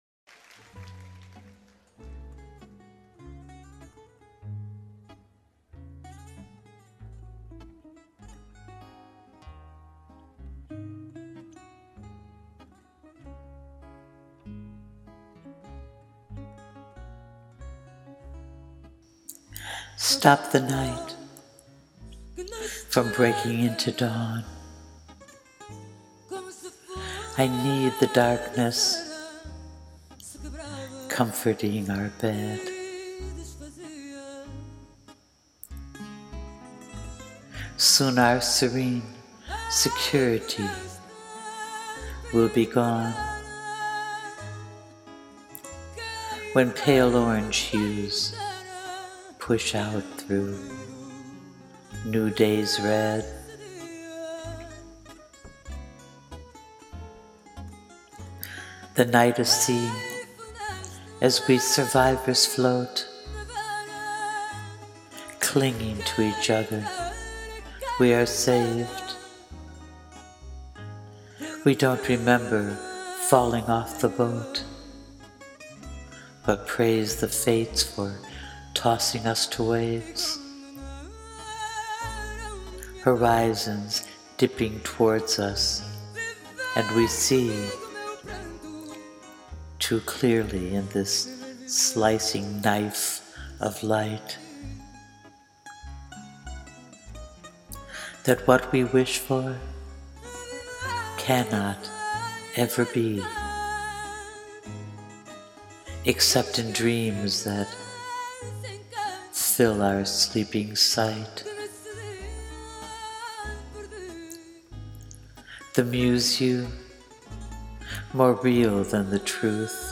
I have made a recording of my reading, which can’t be shown on Spillwords but I do hope you will, come back to this page and listen to it after reading it all on their site.
Please click Play after you have read my poem on Spillwords and enjoy my spoken word to the music of the one and only, Mariza
Such depth of sorrow…a Fado is the most appropriate for this saudade. ♥
The way it paused before the last stanza, and then picked up again, it took my breath away.